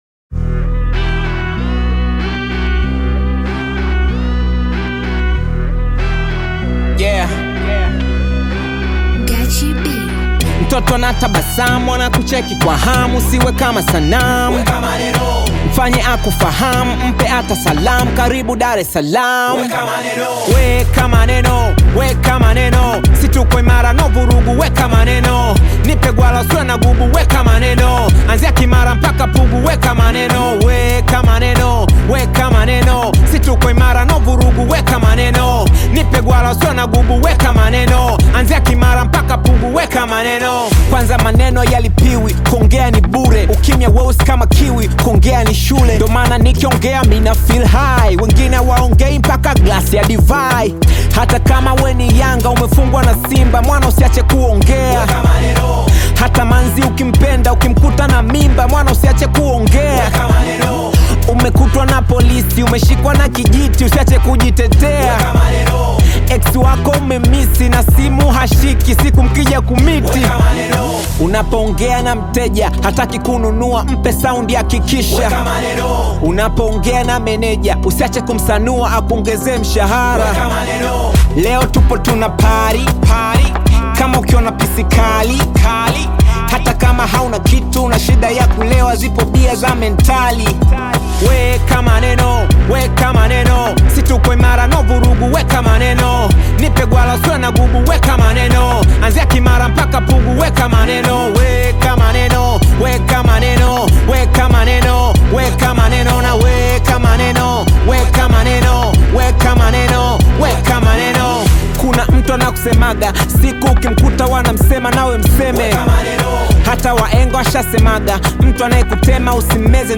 Tanzanian bongo flava artist singer, rapper and songwriter